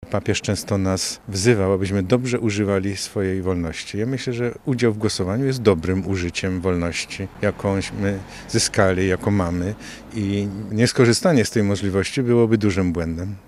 Arcybiskup w rozmowie z Radiem Lublin nawiązał do trwającego Dnia Papieskiego, kiedy wspominamy pierwszą wizytę papieża Jana Pawła II w naszym kraju.